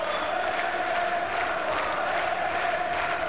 crowd2.wav